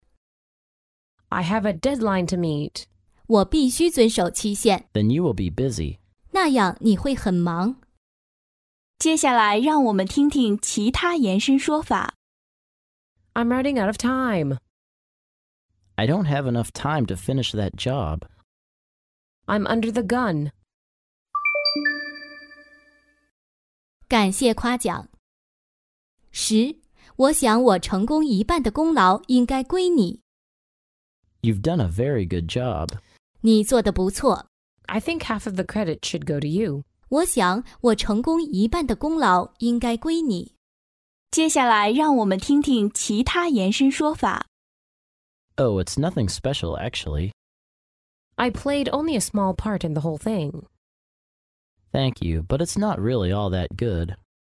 在线英语听力室法律英语就该这么说 第148期:我必须遵守期限的听力文件下载,《法律英语就该这么说》栏目收录各种特定情境中的常用法律英语。真人发音的朗读版帮助网友熟读熟记，在工作中举一反三，游刃有余。